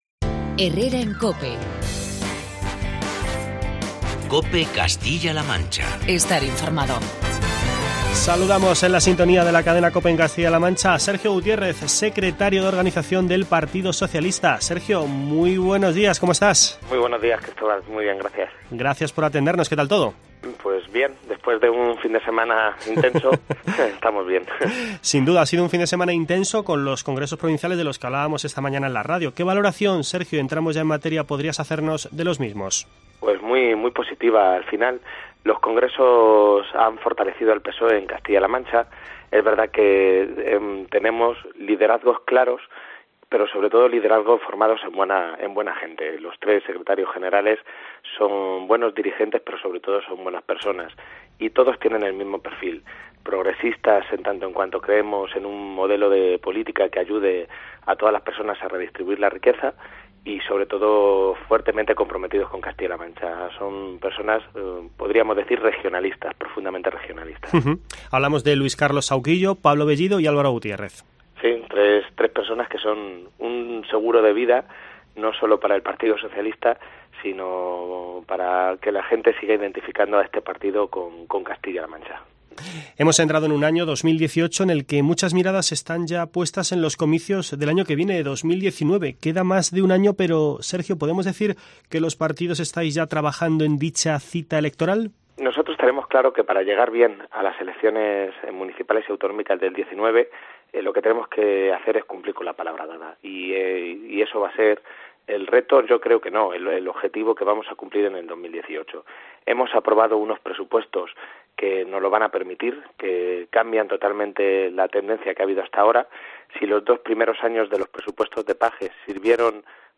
Entrevista con Sergio Gutiérrez, secretario de Organización del PSOE de Castilla-La Mancha